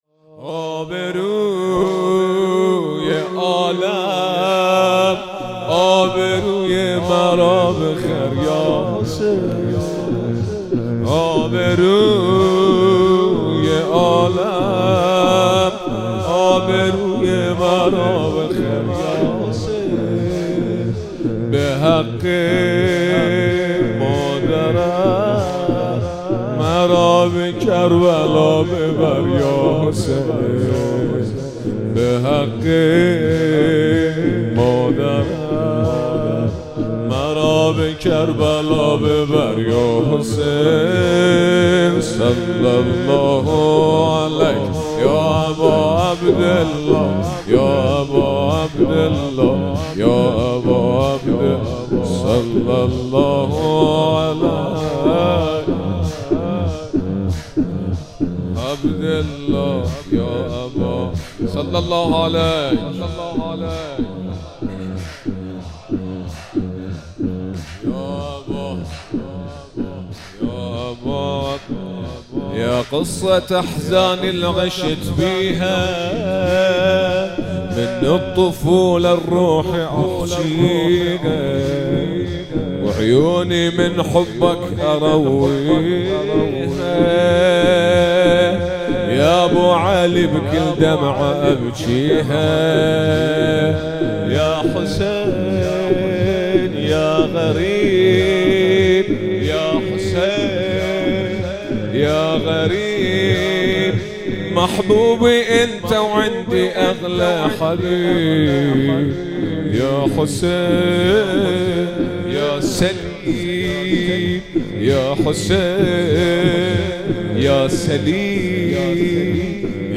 مراسم مناجات خوانی شب چهاردهم ماه رمضان 1444
زمینه- آبروی عالم ابروی مرا بخر یاحسین